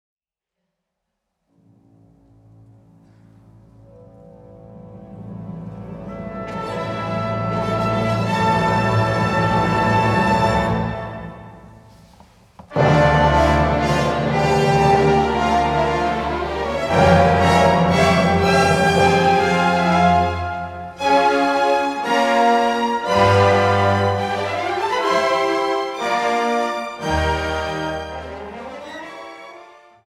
für großes Orchester